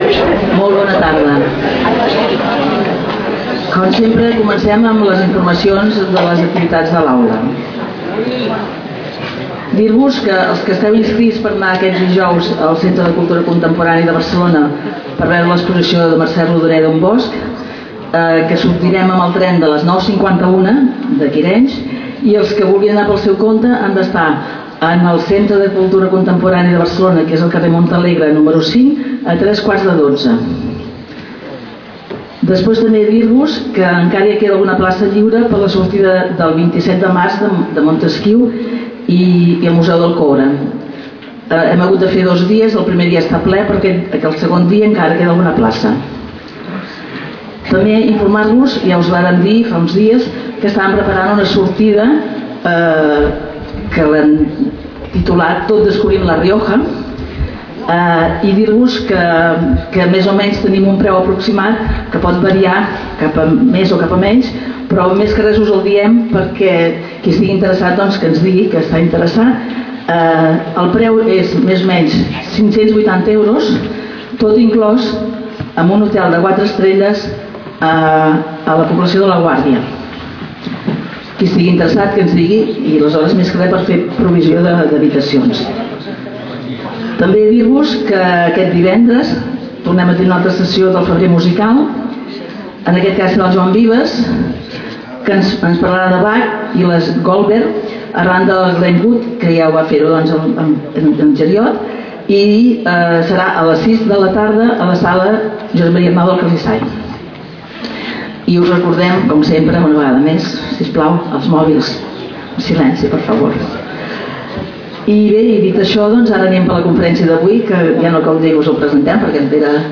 Categoria: Conferències